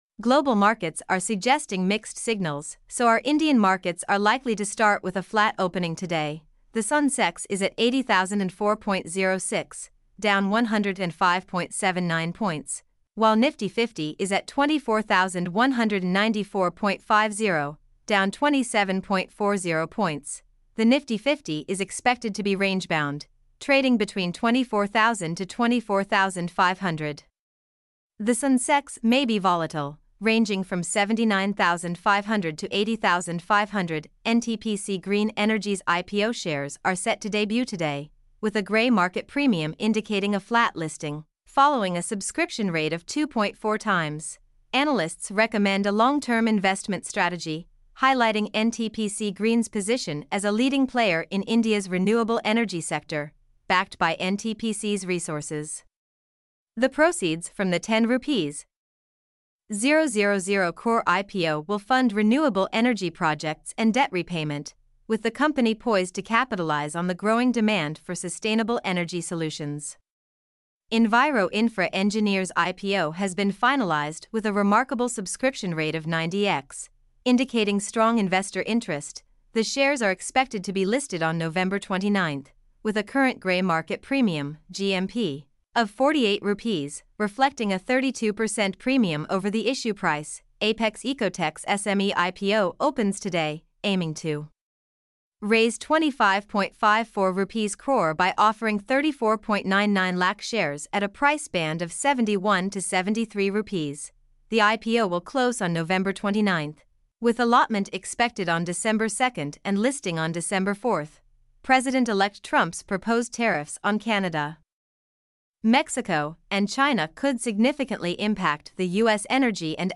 mp3-output-ttsfreedotcom-6.mp3